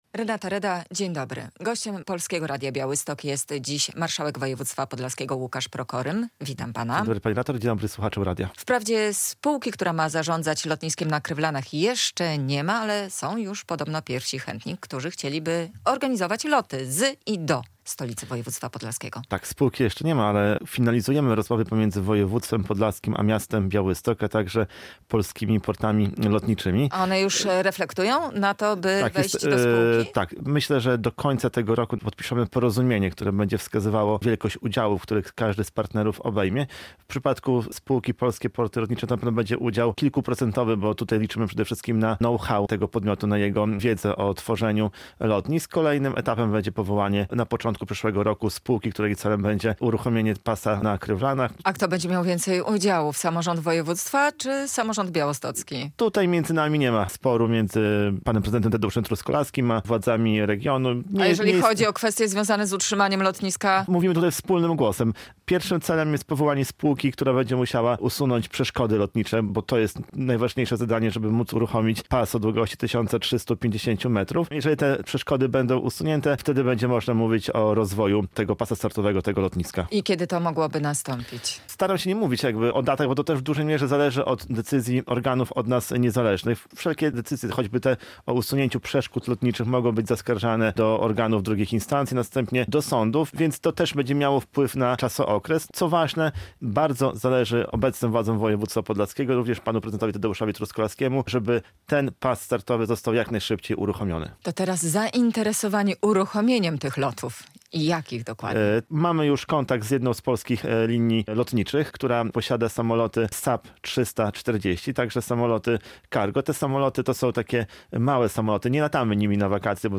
Radio Białystok | Gość | Łukasz Prokorym - marszałek województwa podlaskiego